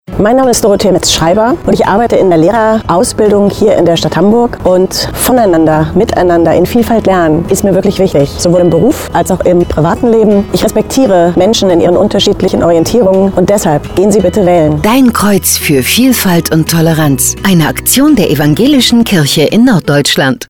Um möglichst viele Menschen zu erreichen, haben wir Radiospots in norddeutschen Sendern geschaltet, die wir in Kooperation mit dem Evangelischen Presseverband Nord produziert haben. Sechs Menschen aus unserer Landeskirche haben mitgemacht und ein „Testimonial“ eingesprochen.